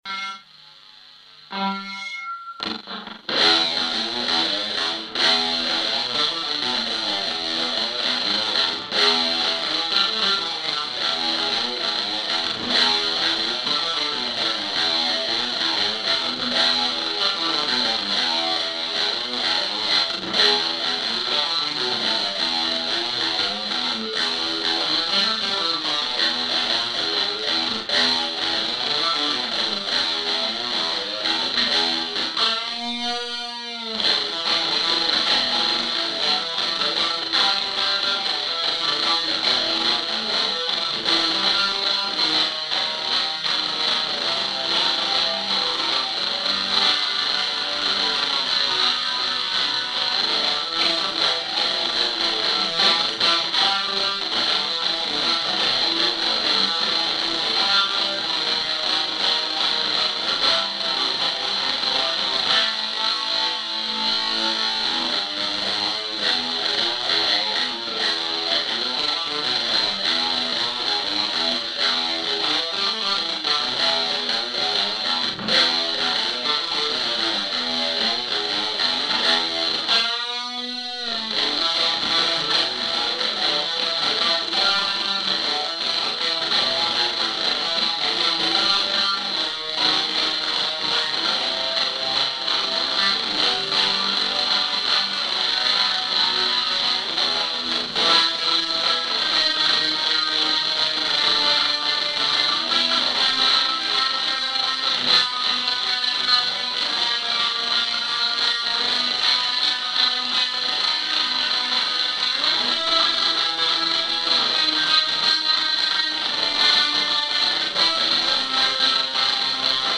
чисто гитара
Музончик обещает неплохой замес.
Я вот думаю позже с вокалом выложить .